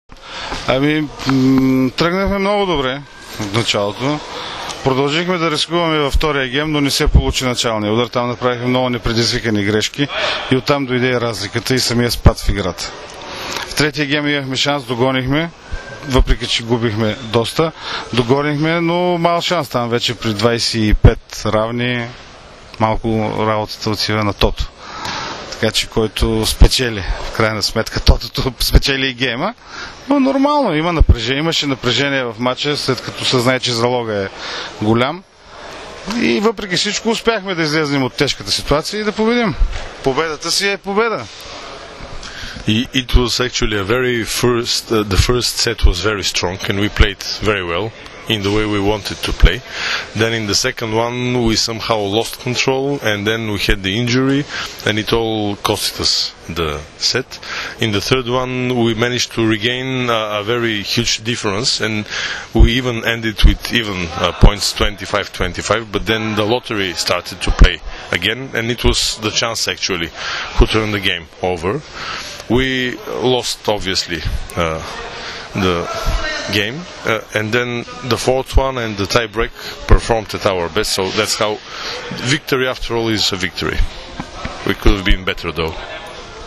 IZJAVA
SA PREVODOM